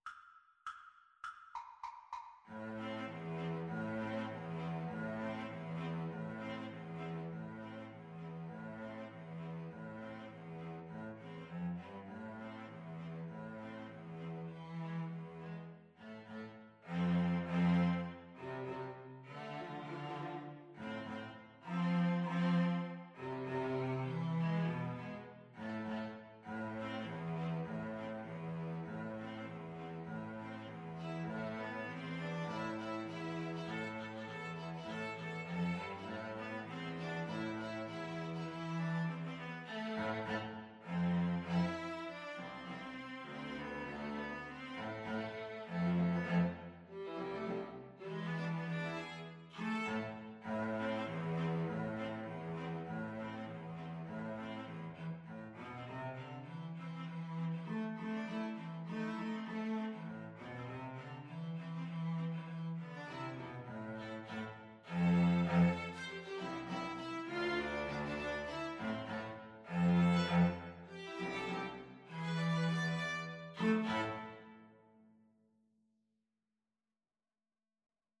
String trio version
Firmly, with a heart of oak! Swung = c.100